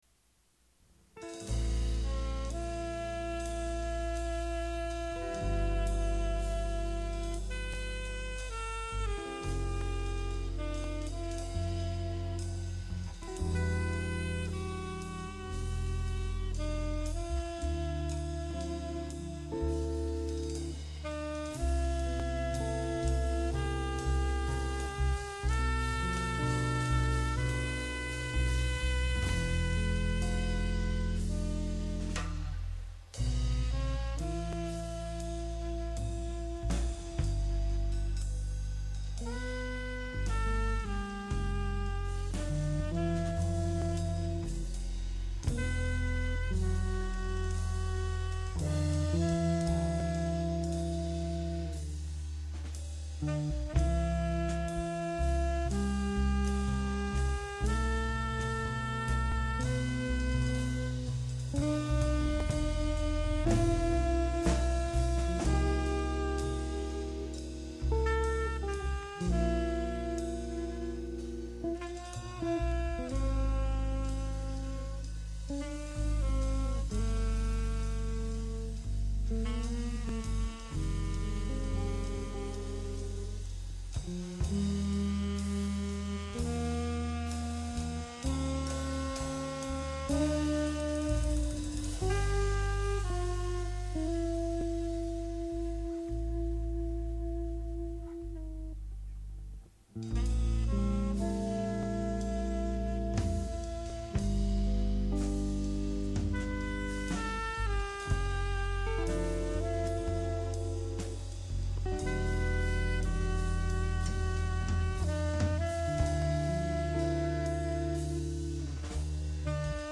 acoustic jazz quartet